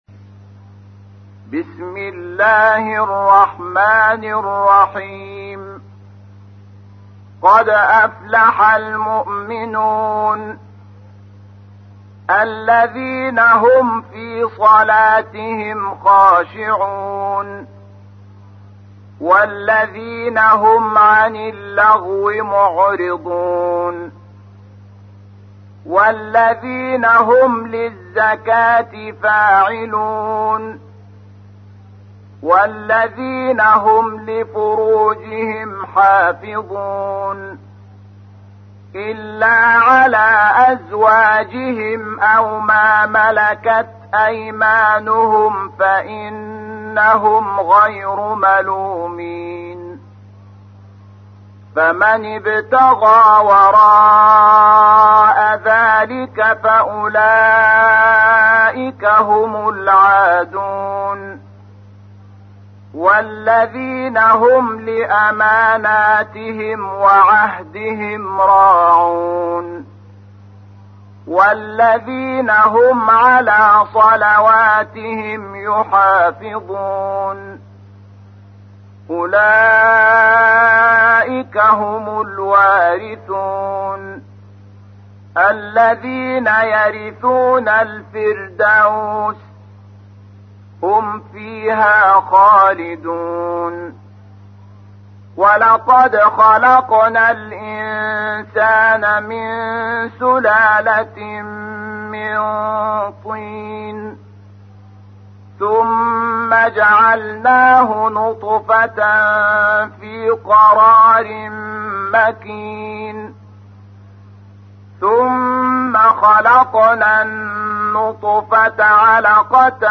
تحميل : 23. سورة المؤمنون / القارئ شحات محمد انور / القرآن الكريم / موقع يا حسين